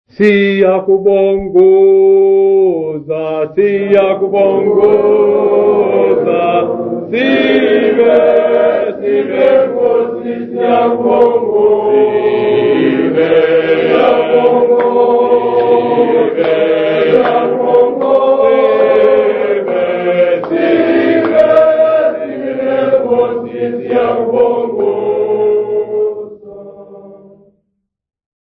Zingisa Seminary Congregation
St Bede's workshop participants
Folk music
Sacred music
Field recordings
Africa South Africa Umtata sa
Catholic mass hymn, accompanied by clapping.
96000Hz 24Bit Stereo